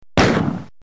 Doom Pistol Soundboard: Play Instant Sound Effect Button
This high-quality sound effect is part of our extensive collection of free, unblocked sound buttons that work on all devices - from smartphones to desktop computers.